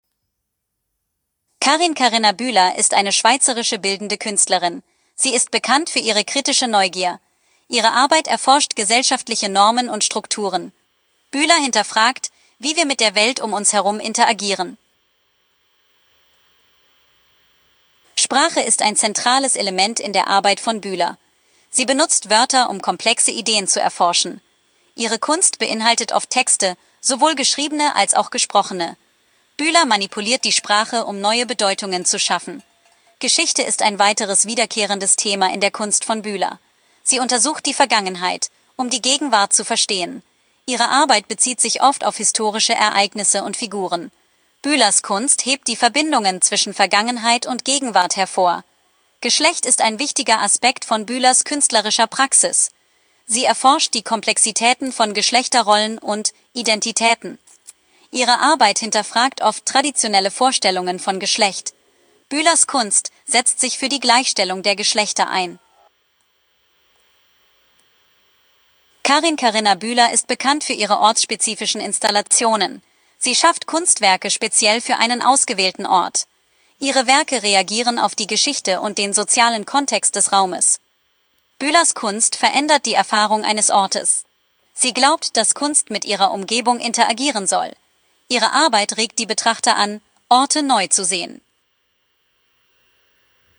Audio-Statement
ki-statement.m4a